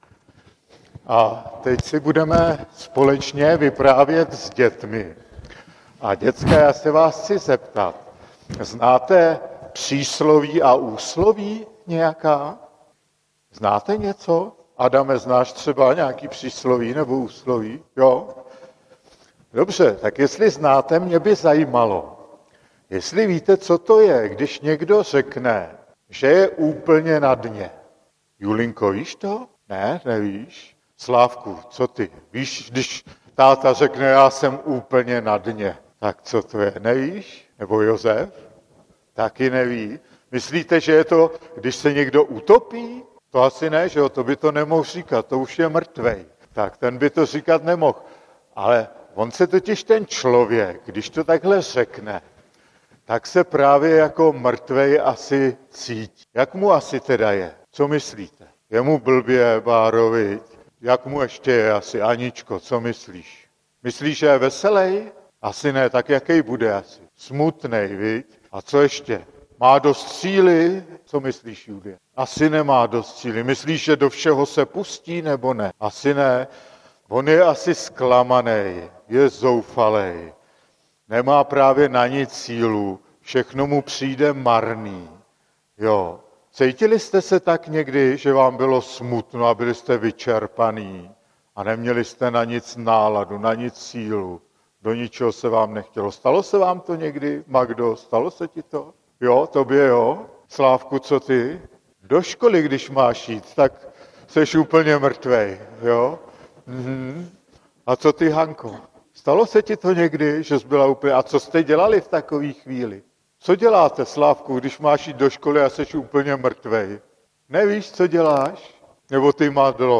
Rodinné bohoslužby 19.2.2017 - Elijáš | Farní sbor ČCE Nové Město na Moravě